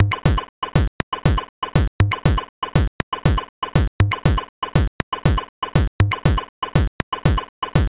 And a few crazy loops, mostly done with random kits and functions.
Blip_TechnoPulse.wav